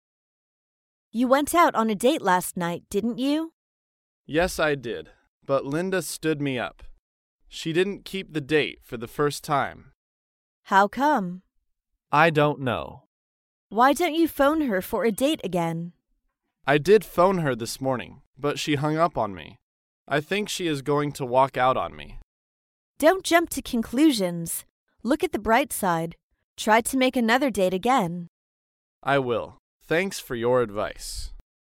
在线英语听力室高频英语口语对话 第174期:情侣幽会的听力文件下载,《高频英语口语对话》栏目包含了日常生活中经常使用的英语情景对话，是学习英语口语，能够帮助英语爱好者在听英语对话的过程中，积累英语口语习语知识，提高英语听说水平，并通过栏目中的中英文字幕和音频MP3文件，提高英语语感。